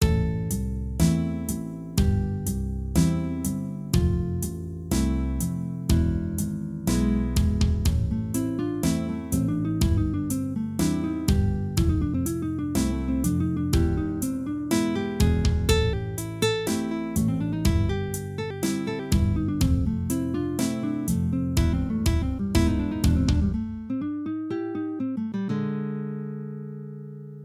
So I wanted to compose something with a bluesy fell.